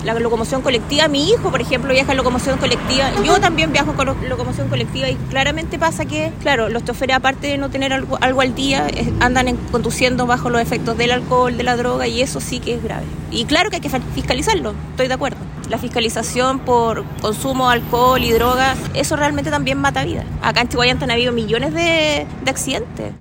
cuna-conductora.mp3